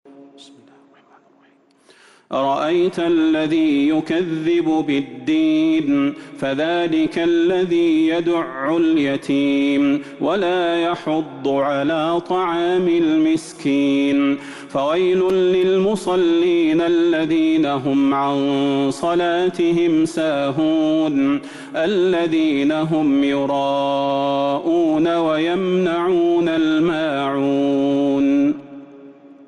سورة الماعون Surat Al-Ma'oon من تراويح المسجد النبوي 1442هـ > مصحف تراويح الحرم النبوي عام 1442هـ > المصحف - تلاوات الحرمين